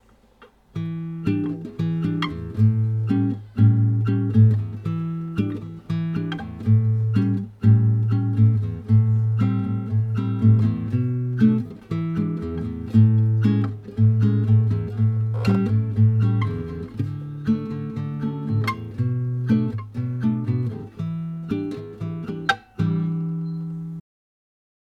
- Chitarra Acustica - Jamble Forum
Mi sembra un filo sottile come rec...prossima volta prova con il condensatore a 20/30cm ad altezza buca ma che punti leggermente verso il manico ;)